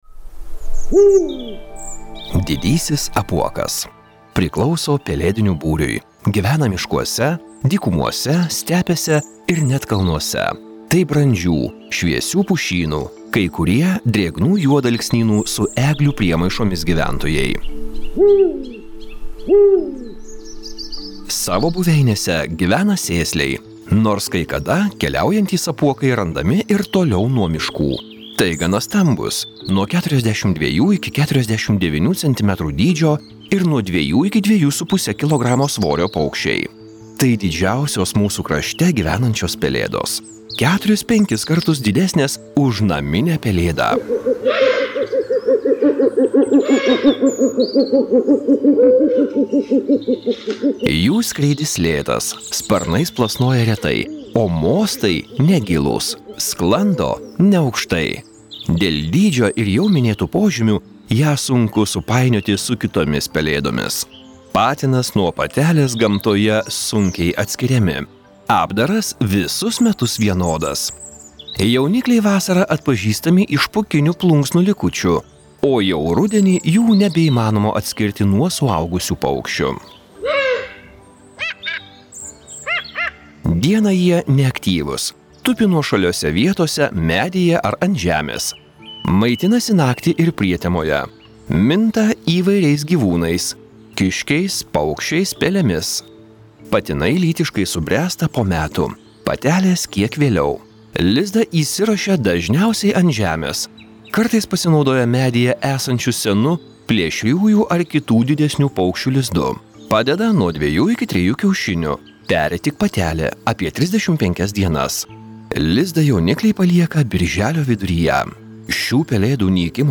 Žvėrinčius – Apuokas
zverincius-apuokas.mp3